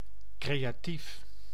Ääntäminen
IPA: [kɾe.a.tiːf]